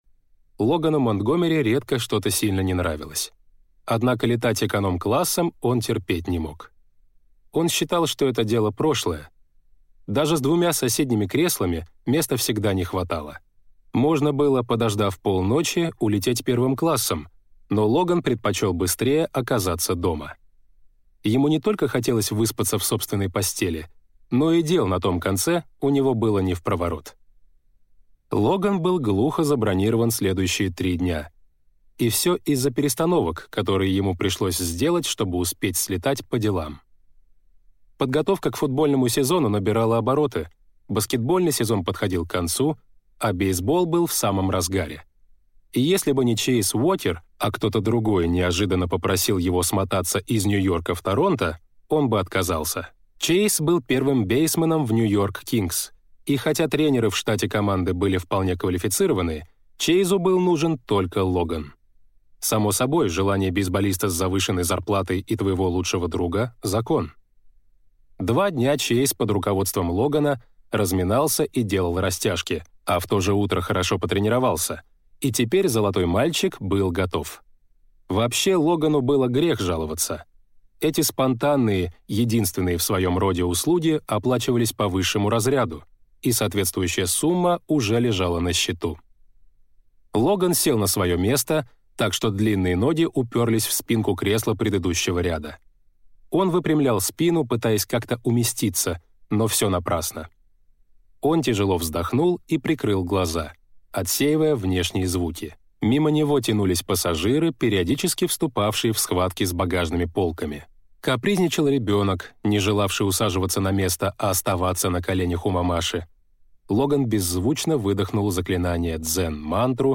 Аудиокнига Любовь без размера | Библиотека аудиокниг